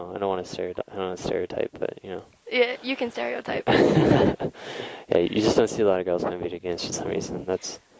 audio examples for Chapter 7: Expressing Positive Assessment
Uses in Conversation